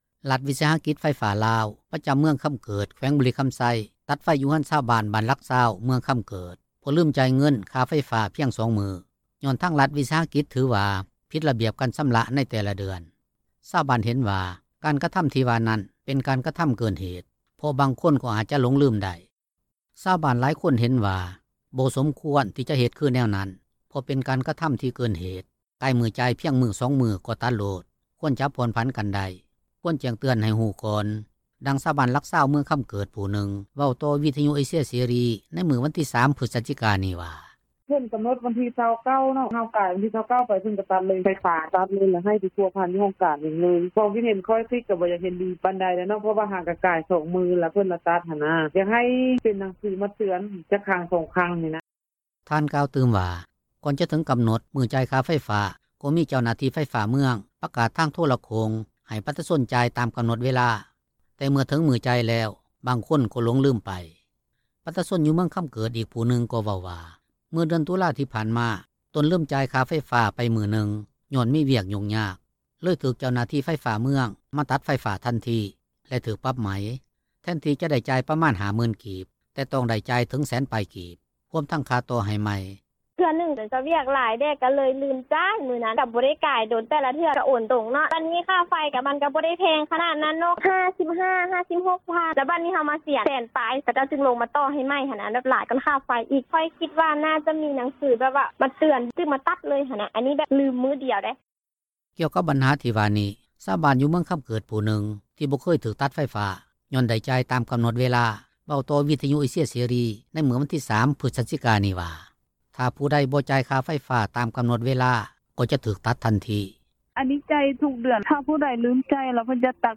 ດັ່ງຊາວບ້ານ ບ້ານຫລັກ 20, ເມືອງຄໍາເກີດ ຜູ່ນຶ່ງເວົ້າຕໍ່ວິທຍຸ ເອເຊັຽເສຣີ ໃນມື້ວັນທີ 3 ພຶສຈິການີ້ວ່າ: